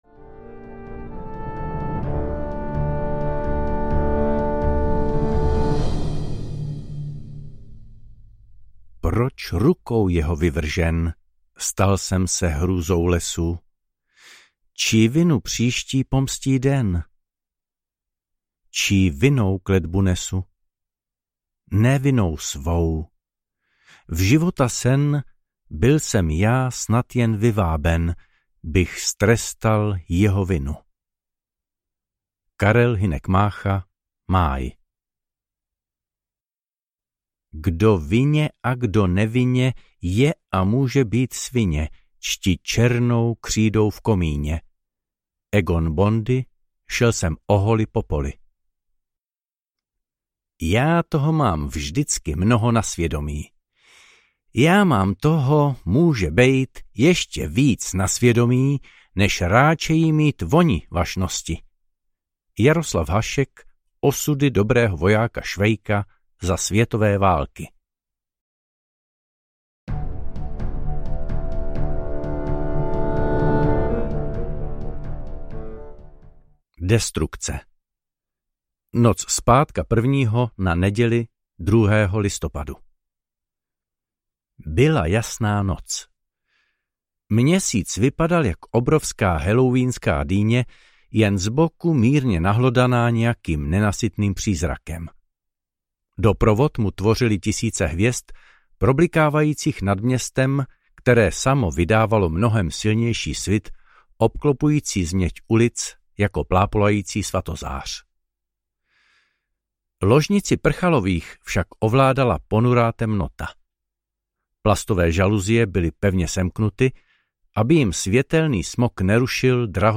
Hřbitov trpaslíků audiokniha
Ukázka z knihy
hrbitov-trpasliku-audiokniha